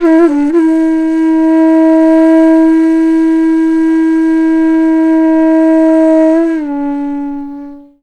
FLUTE-A04 -R.wav